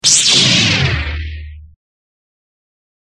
レーザーサーベル 着信音
レーザーサーベルの音。高い音が繰り返し鳴り響く「ズンズンズン」という音と、低い音が繰り返し鳴り響く「ブーブーブー」という音が混ざり合ったものとなっています。この音は、エンターテインメントの世界において、特にSFやファンタジー作品で用いられ、武器の発射音や戦闘音としてよく使われます。